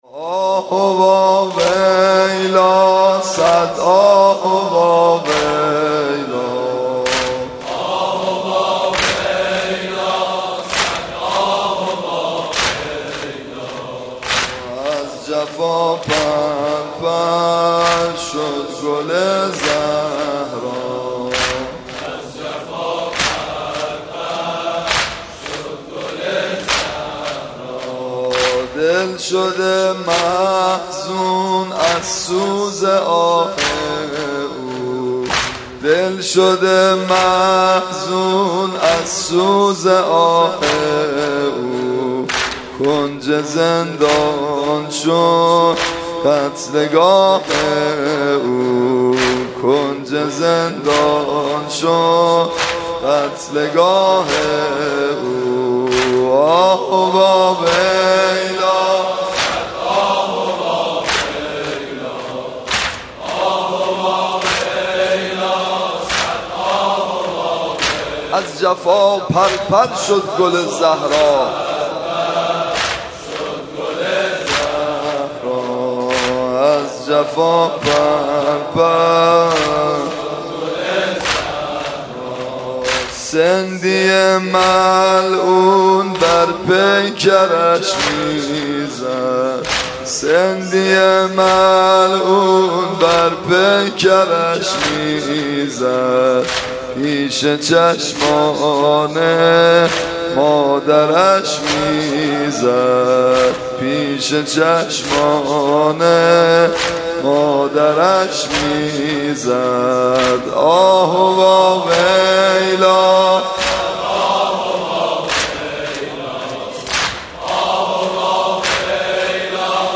روضه و مرثیه ها